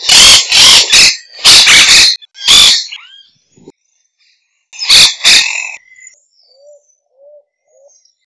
La nota usual en reposo o en vuelo es una chapa metálica áspera que a veces termina con un estribillo. Otras vocalizaciones incluyen una charla sobre las tuberías, quak quaki quak-wi quarr, además de chirridos penetrantes, graznidos rápidamente repetidos en el cortejo y charla conversacional mientras se alimenta.